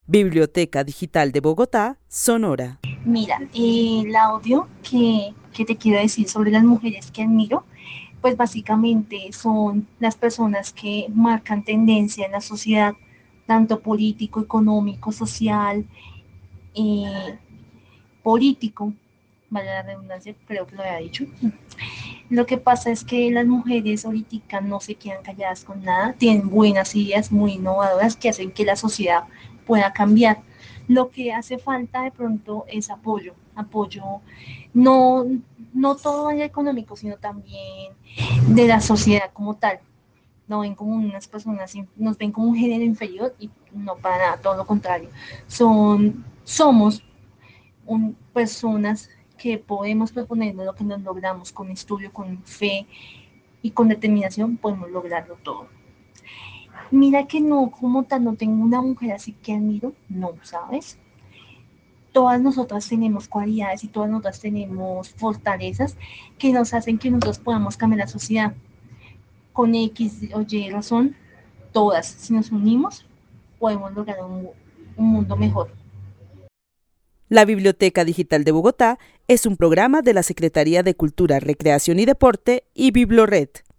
Narración oral de una mujer que vive en la ciudad de Bogotá quien admira a las mujeres que marcan tendencia en la sociedad en lo político, económico y social. Resalta que ahora las mujeres no se quedan calladas y tienen buenas ideas para que la sociedad pueda cambiar, aunque falta apoyo, no solo en lo económico, porque aun se considera a las mujeres como un género inferior.
El testimonio fue recolectado en el marco del laboratorio de co-creación "Postales sonoras: mujeres escuchando mujeres" de la línea Cultura Digital e Innovación de la Red Distrital de Bibliotecas Públicas de Bogotá - BibloRed.